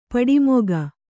♪ paḍi moga